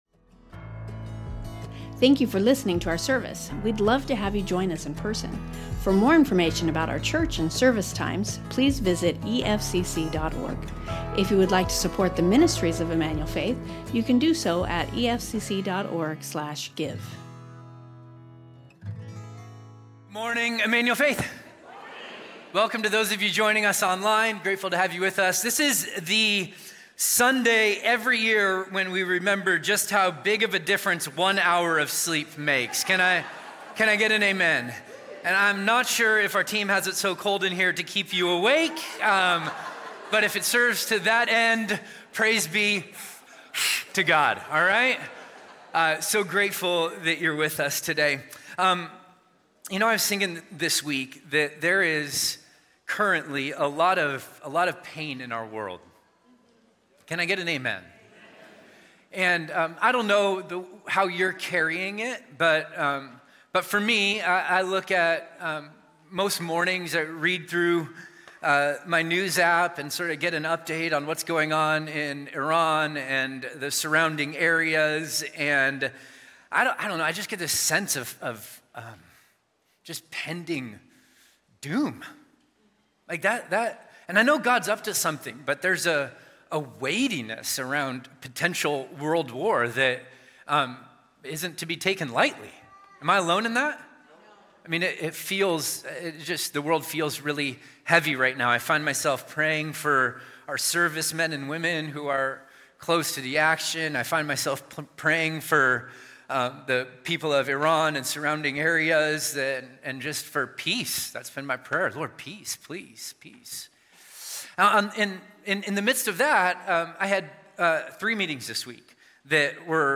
Emmanuel Faith Sermon Podcast Renewal | Jeremiah 29:1-14 Mar 09 2026 | 00:43:55 1x 00:00 / 00:43:55 Subscribe Share Spotify Amazon Music RSS Feed Share Link Embed